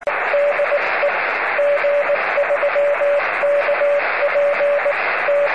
「もうこのEsも終わりだな」既にQRTモードに入りつつあった頃、バンドの下の方でCWのシグナルを発見した。
やはりローカルのシグナルであった。